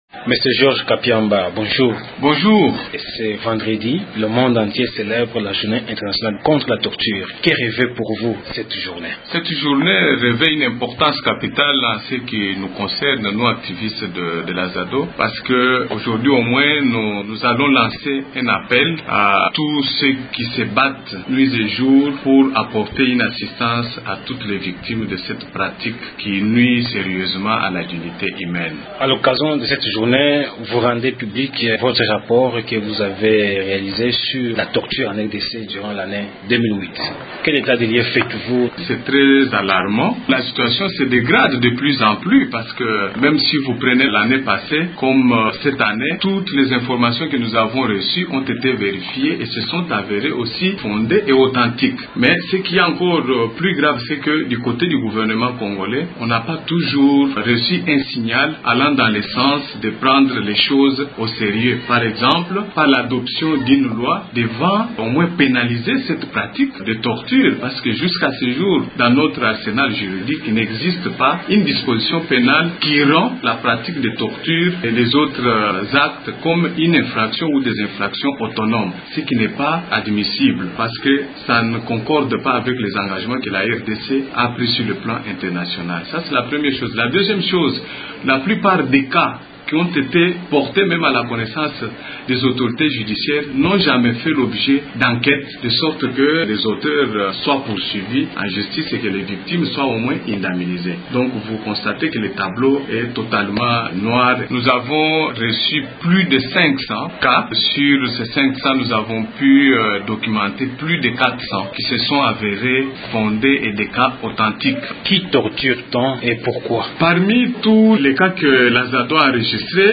s’entretient à ce sujet avec